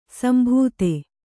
♪ sambhūte